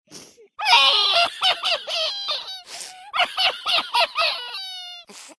avatar_emotion_very_sad.ogg